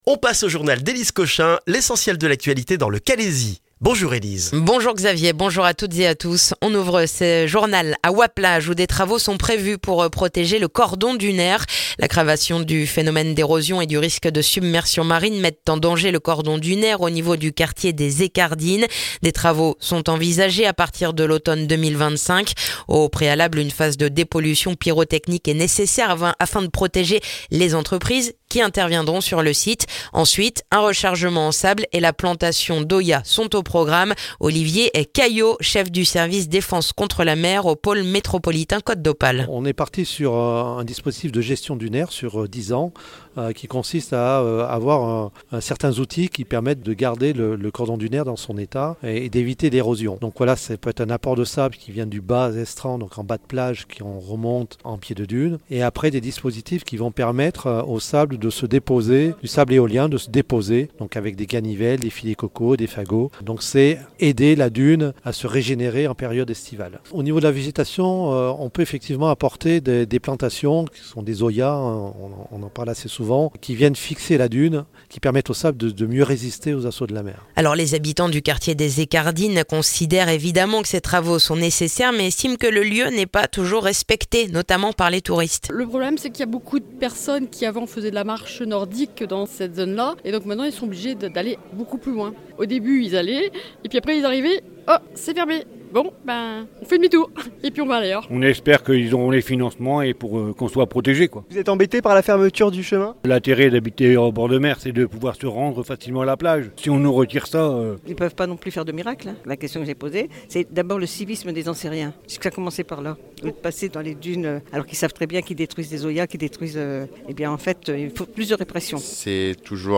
Le journal du vendredi 11 octobre dans le calaisis